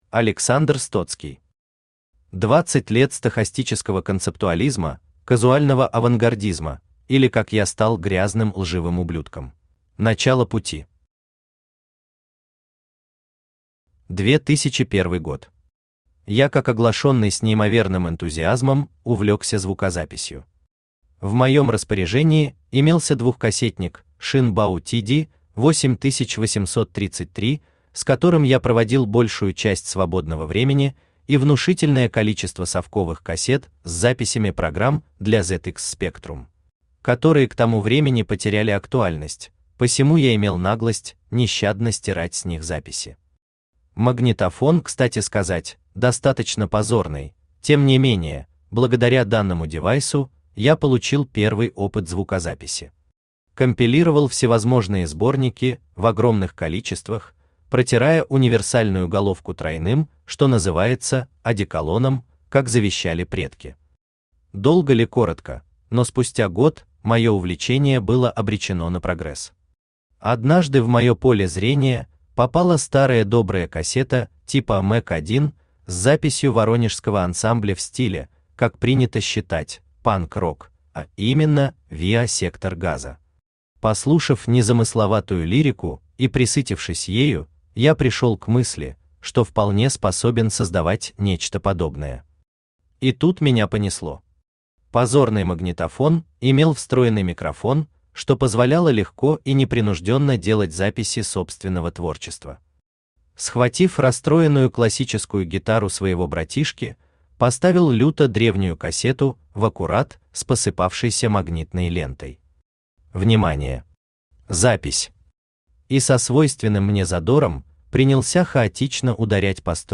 Аудиокнига 20 лет стохастического концептуализма, казуального авангардизма, или Как я стал грязным лживым ублюдком | Библиотека аудиокниг